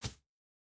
Bare Step Grass Hard B.wav